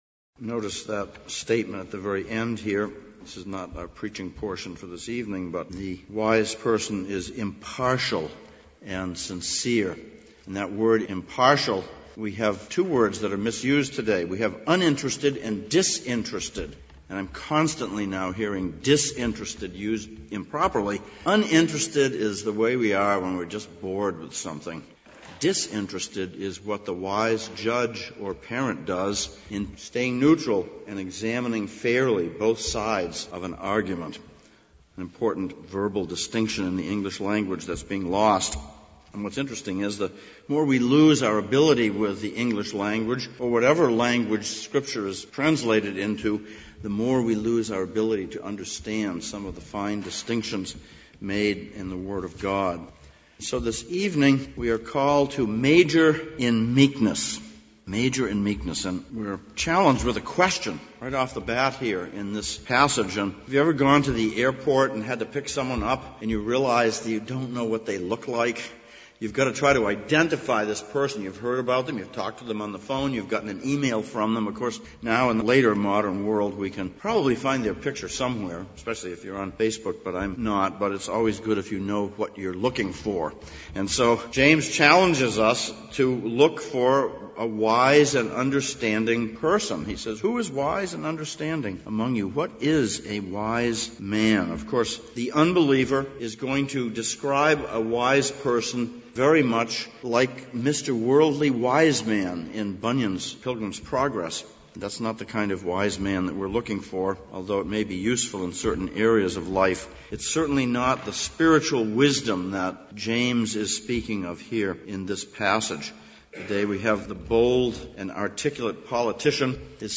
James Passage: James 3:13-18, Proverbs 26:1-16 Service Type: Sunday Evening Sermon text